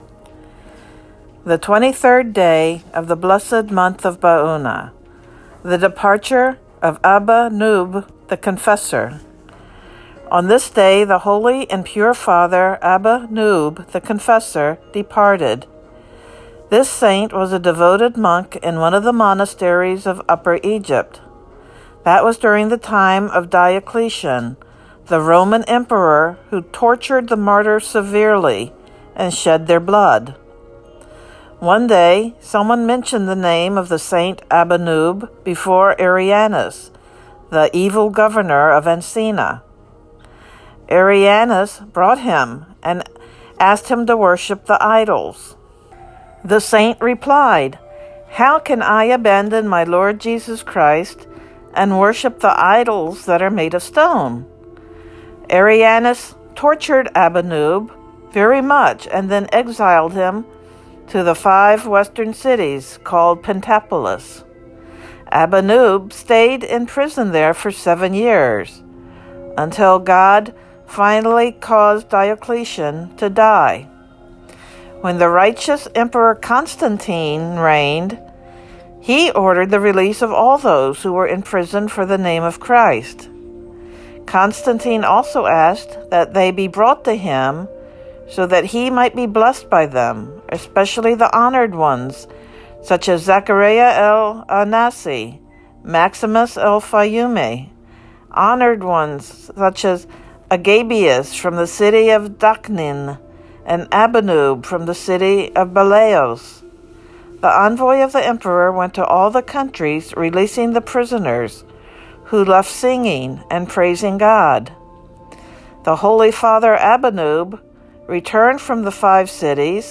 Synaxarium readings for the 23rd day of the month of Baounah